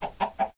sounds / mob / chicken / say1.ogg